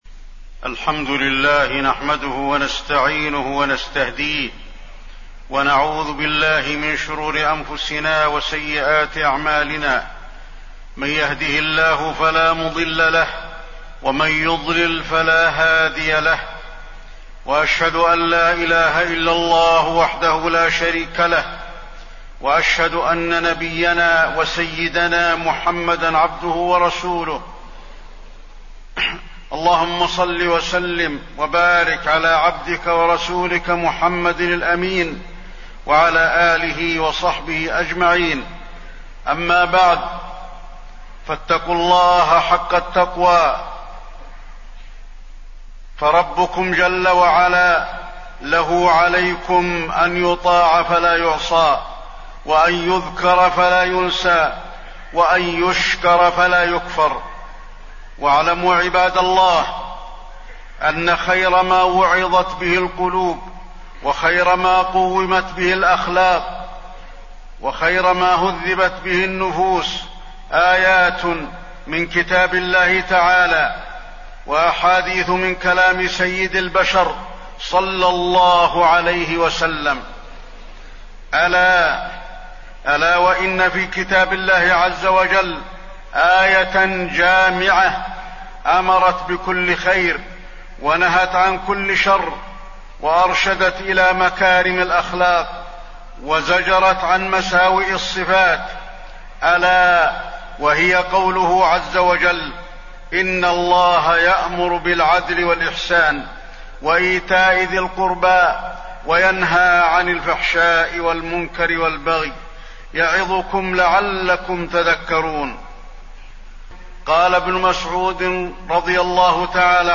تاريخ النشر ٢٤ ربيع الثاني ١٤٣١ هـ المكان: المسجد النبوي الشيخ: فضيلة الشيخ د. علي بن عبدالرحمن الحذيفي فضيلة الشيخ د. علي بن عبدالرحمن الحذيفي العدل